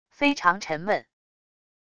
非常沉闷wav音频